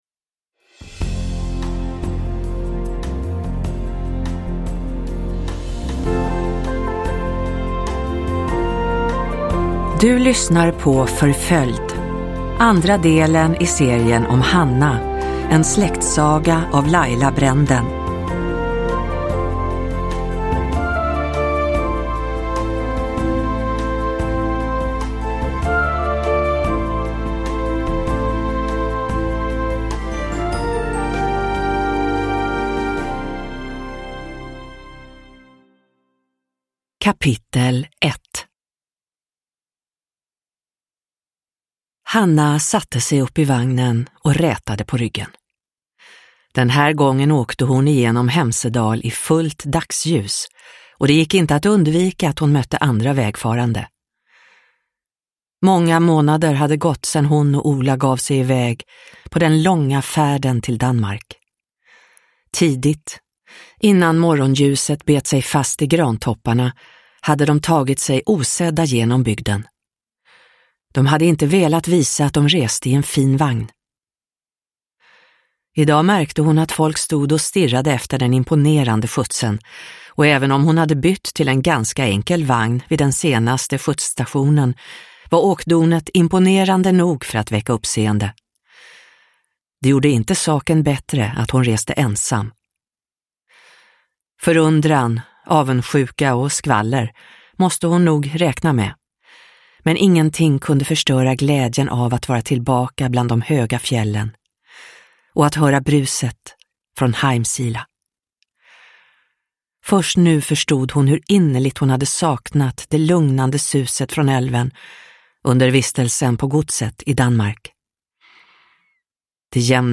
Förföljd – Ljudbok – Laddas ner